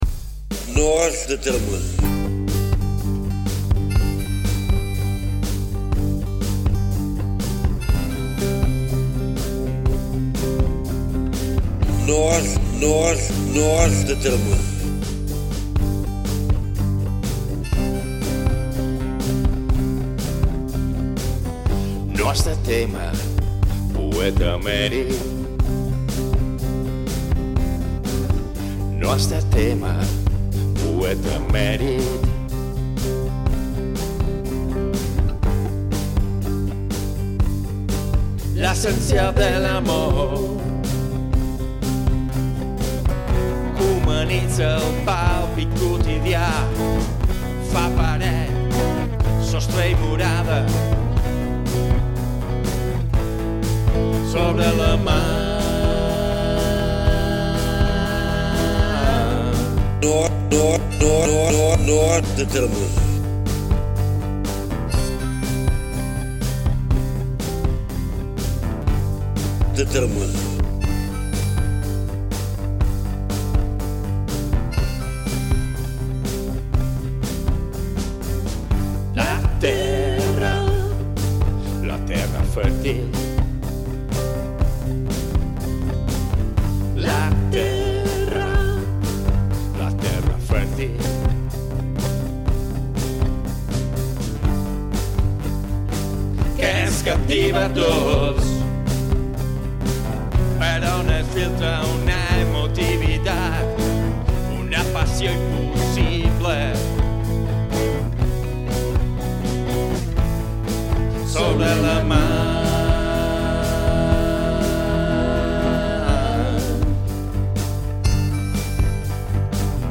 Enregistrat a Can Bahia Studio Sant Josep de sa Talaia
Cançó